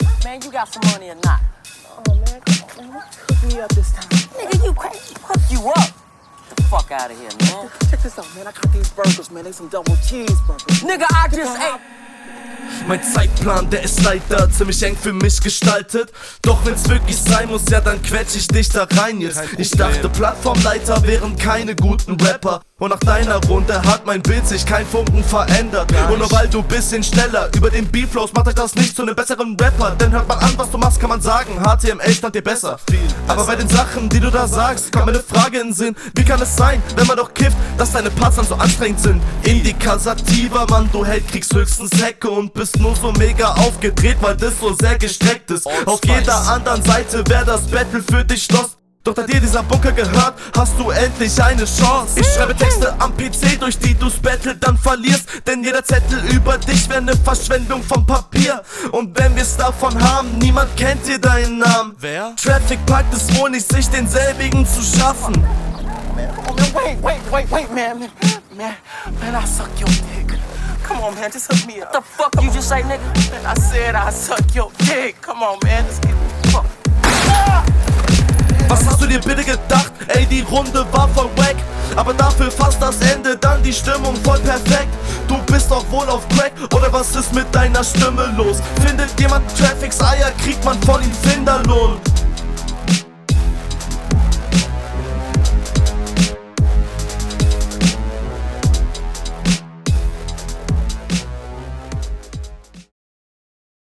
Flow: Auch du hast einen guten Flow welcher On Point ist.